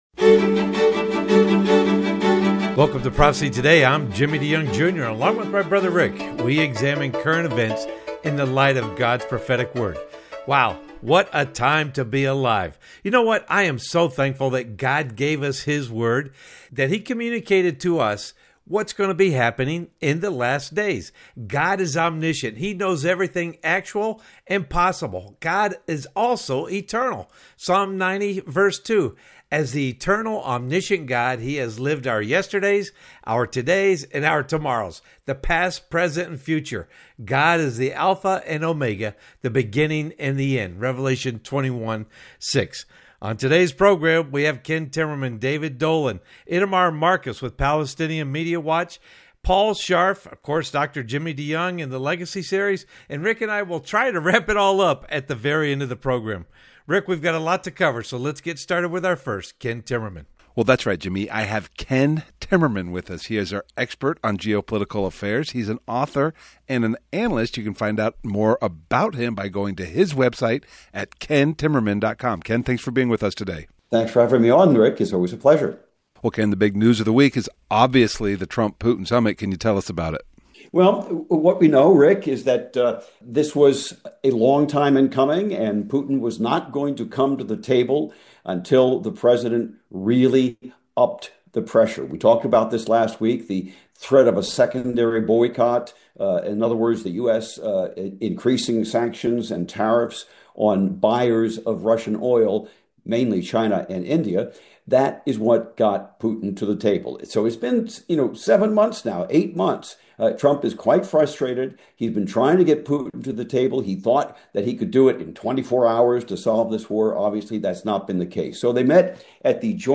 and ‘Prophecy Partners’ on the Prophecy Today Radio Broadcast heard on over 400 stations around the world…